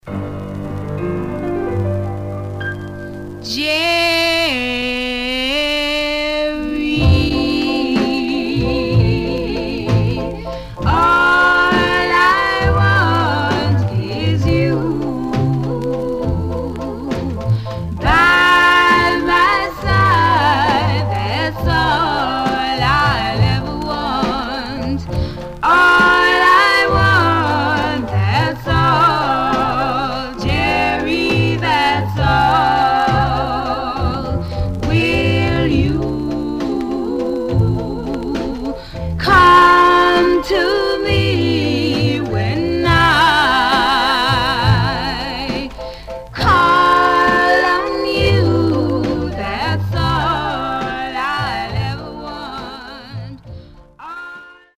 Stereo/mono Mono
Black Female Group